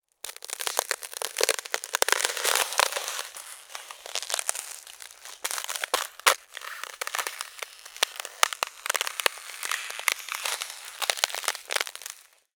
Звуки трескающегося стекла
Стекло покрывается трещинами